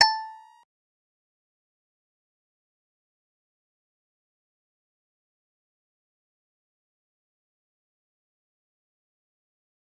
G_Musicbox-A5-pp.wav